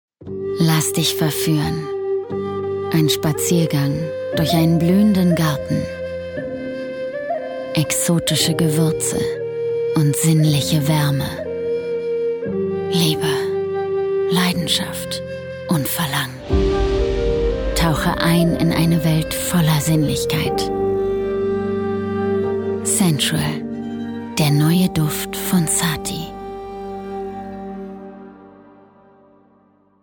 Sprachproben
Booking Sprecherin